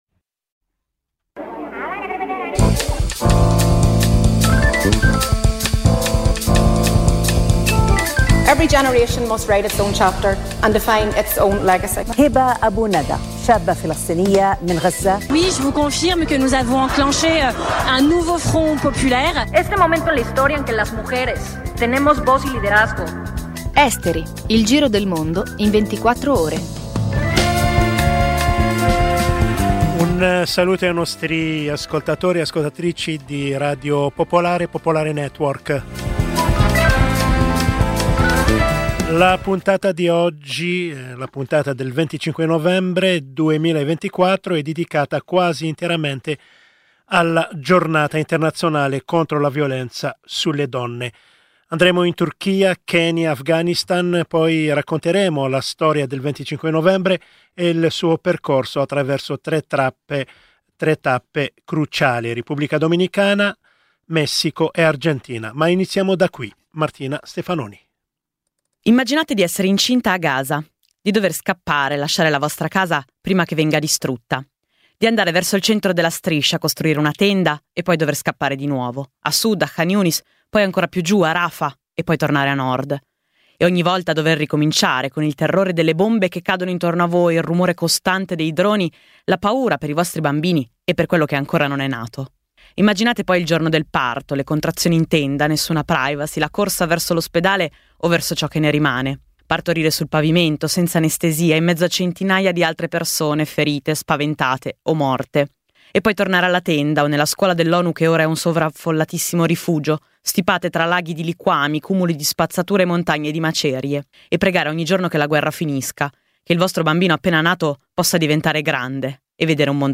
Trovi l’intervista al minuto 11:36